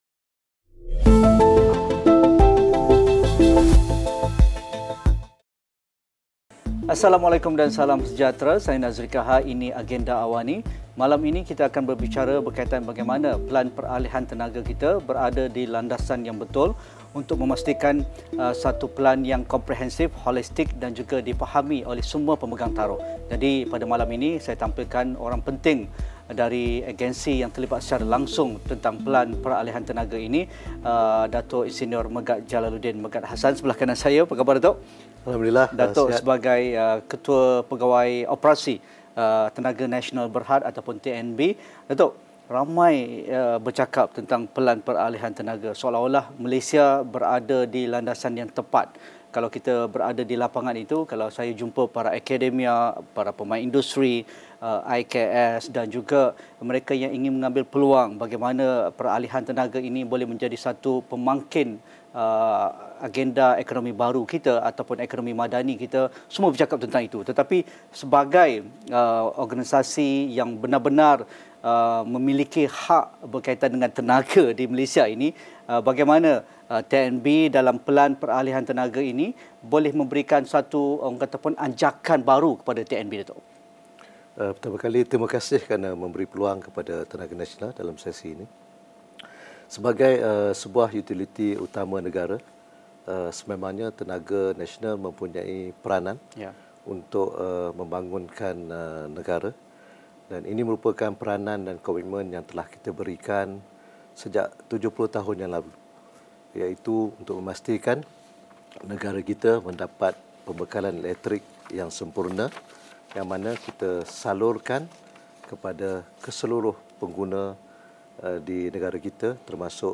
Diskusi 9 malam.